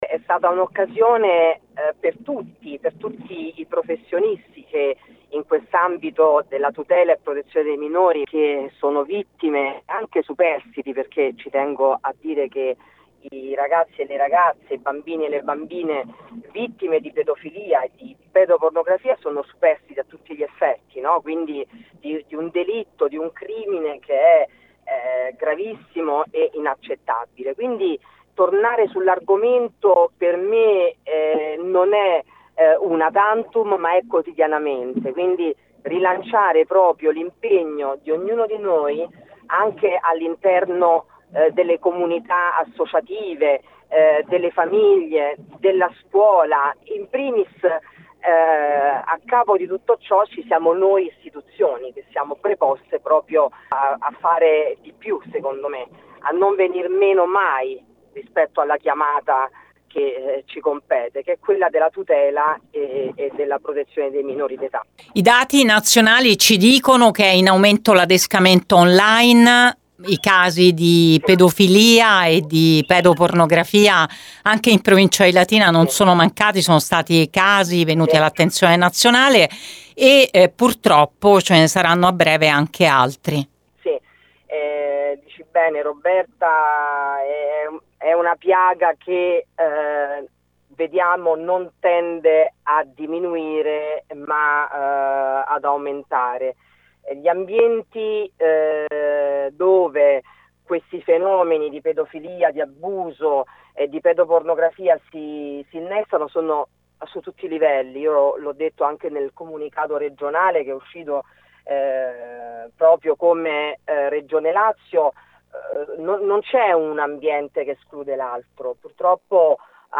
L’intervento della Garante su Gr Latina